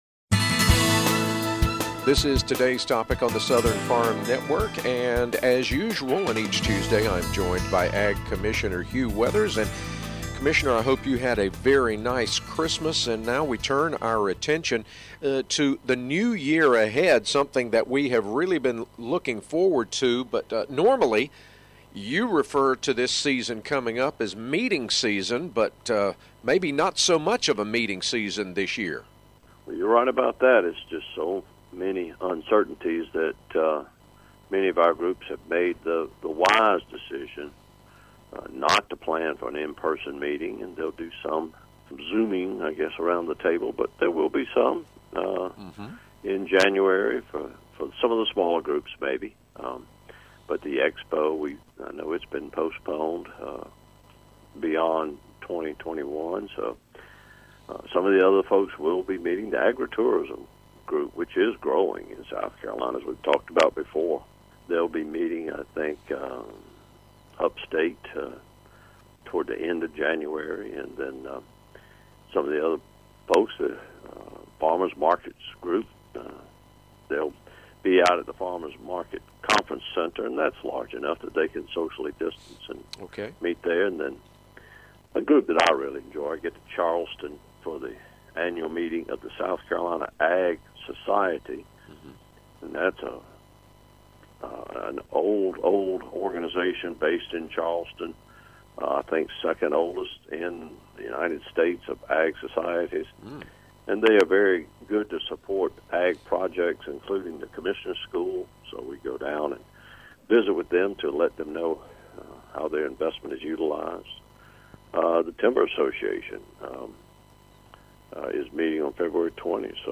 Every week Commissioner Hugh Weathers answers questions about what’s going on with agriculture in our state with The Southern Farm Network.